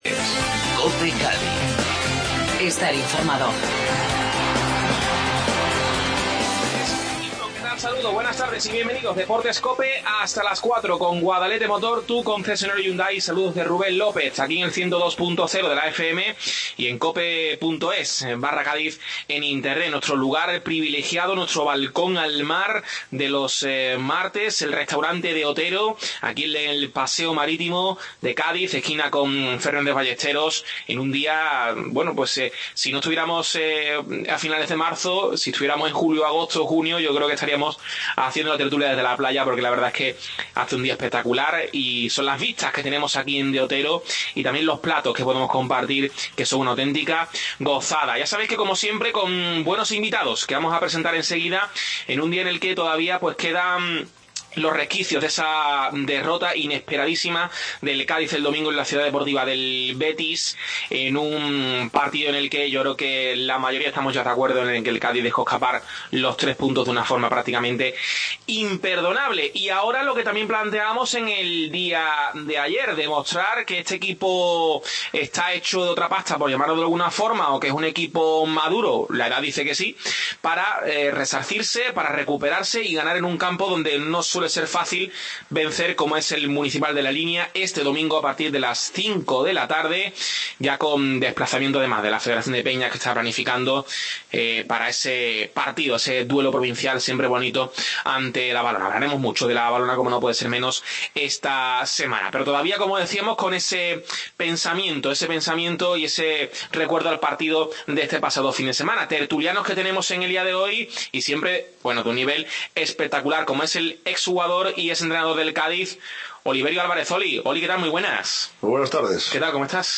Desde el Restauante De Otero tertulia